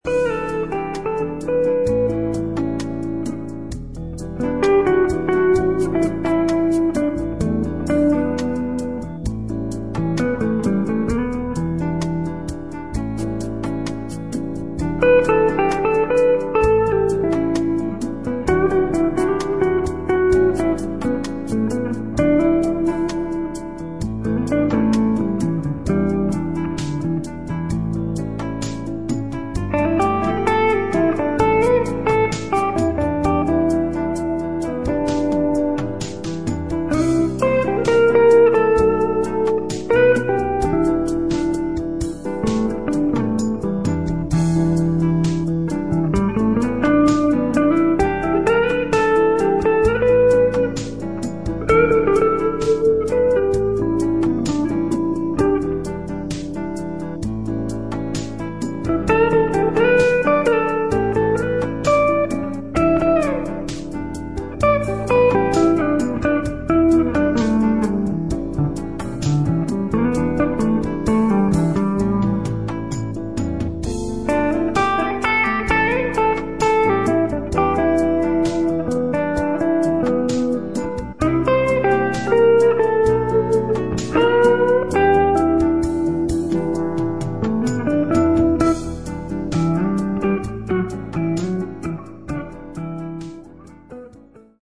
A lounge album with synthesised backings.
Nice guitar tone from my Gibson 335 on this one.